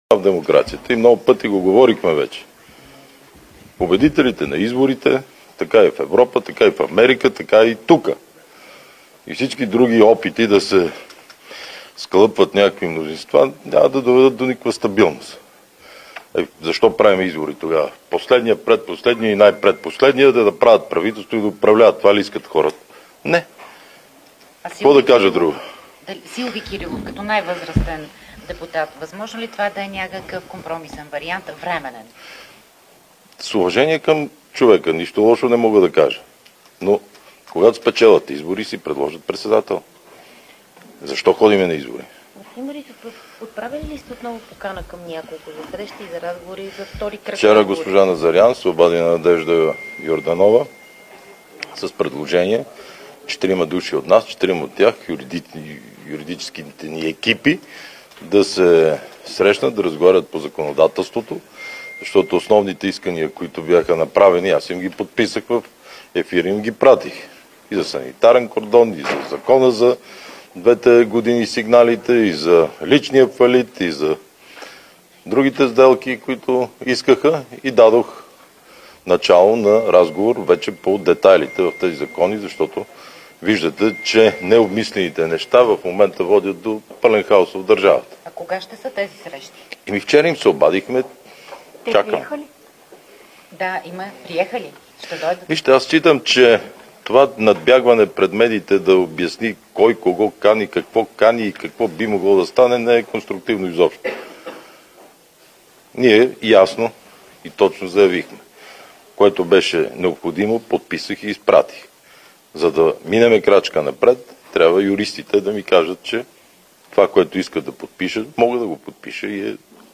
10.55 - Брифинг на председателя на ГЕРБ Бойко Борисов. - директно от мястото на събитието (Народното събрание)